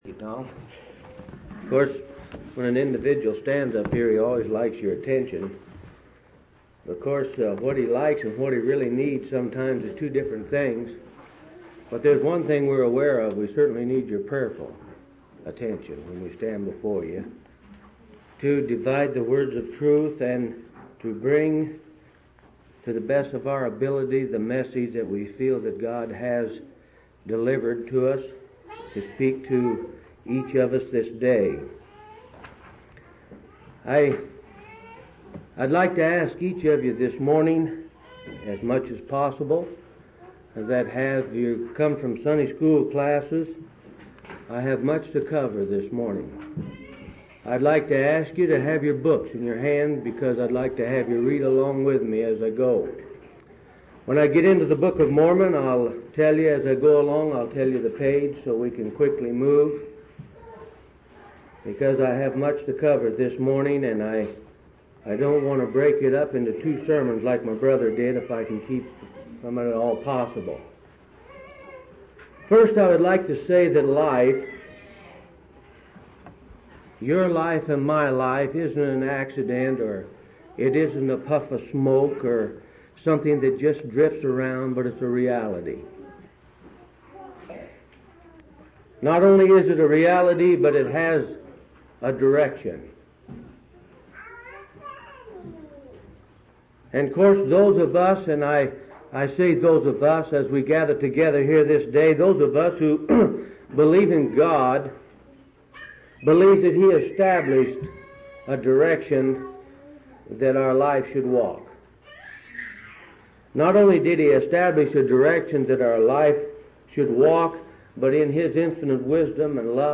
2/27/1983 Location: Phoenix Local Event